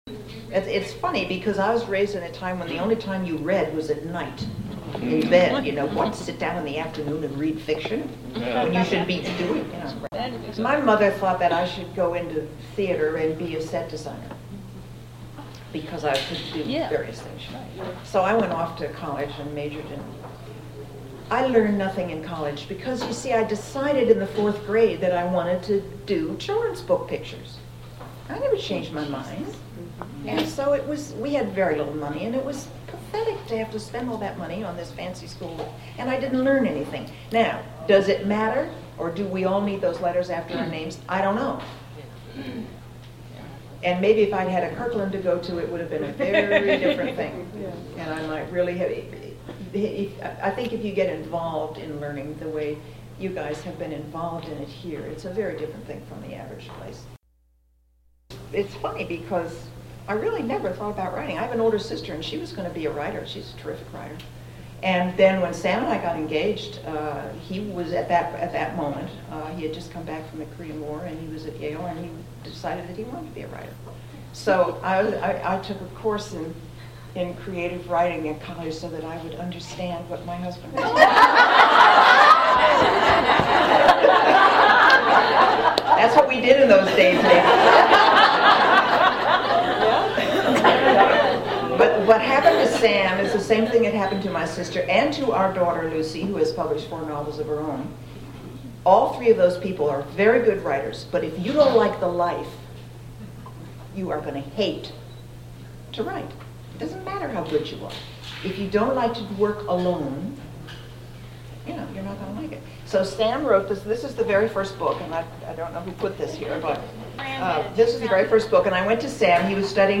During the 2007 All-Kirkland Reunion, Natalie Babbitt read from her latest book, Jack Plank Tells Tales, and took questions from the audience.  Click on the triangle below to hear a brief sample of her responses: